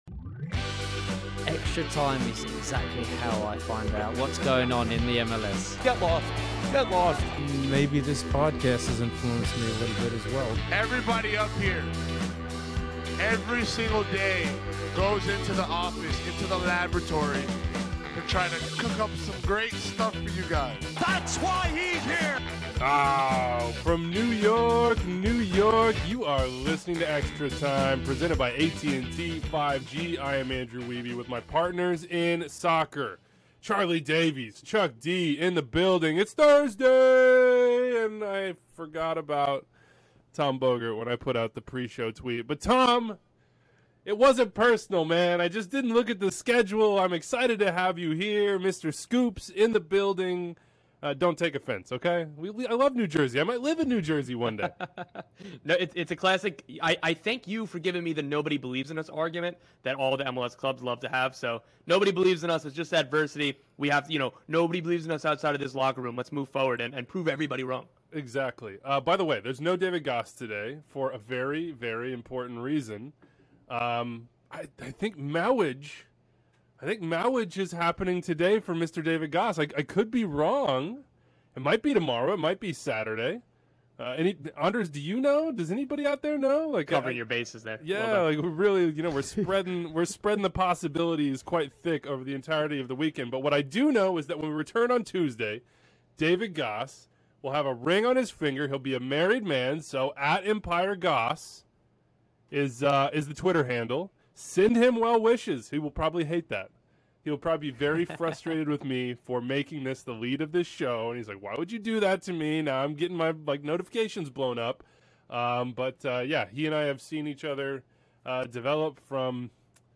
Sneak peak: 1-on-1 with Gregg Berhalter